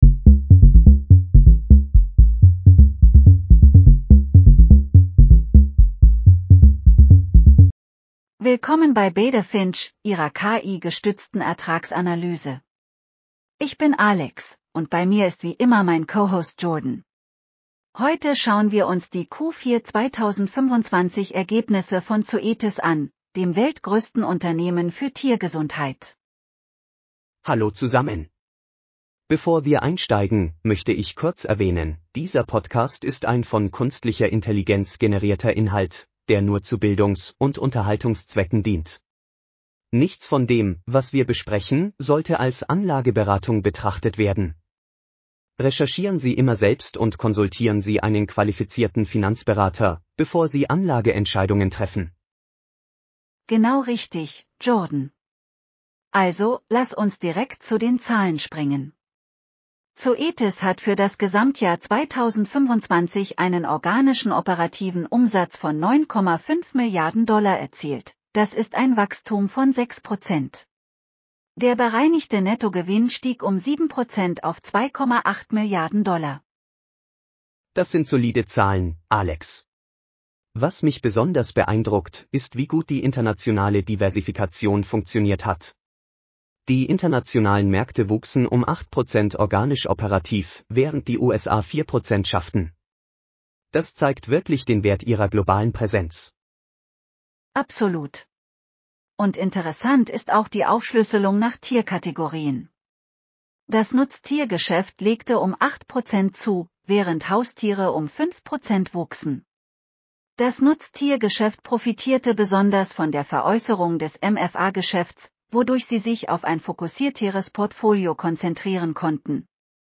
Beta Finch - KI-generierte Ertragsanalyse